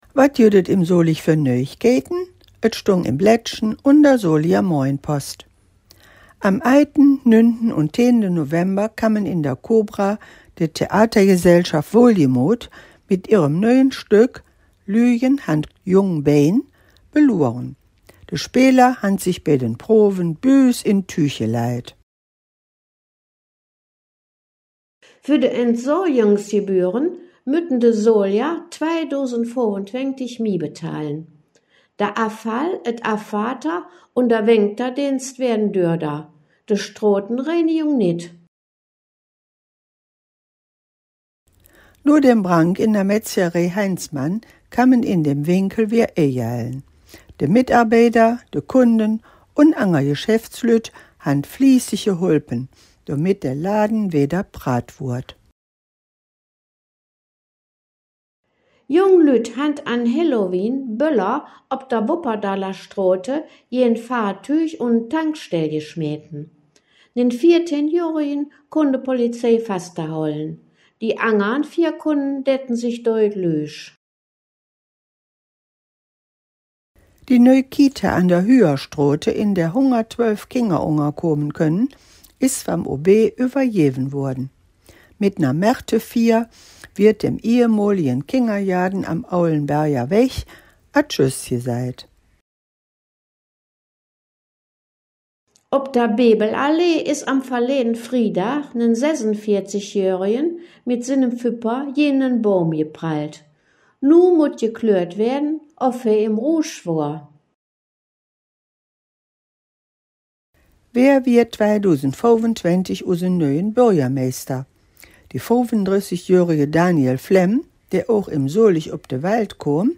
In dieser Folge "Dös Weeke em Solig" blicken de Hangkgeschmedden in Solinger Platt auf die Nachrichten vom 27. Oktober bis zum 07. November 2024 zurück.